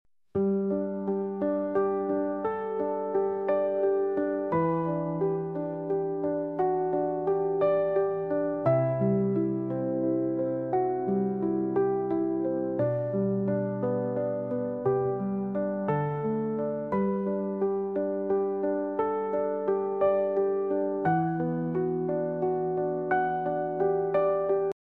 TD798 Leno rapier loom for jute fabric weaving